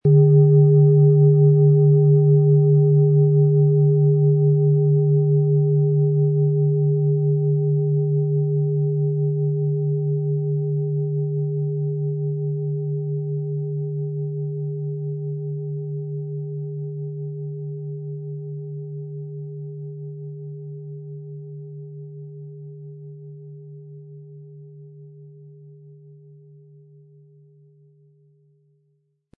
• Tiefster Ton: Mond
• Höchster Ton: Pluto
PlanetentöneSaturn & Mond & Pluto (Höchster Ton)
MaterialBronze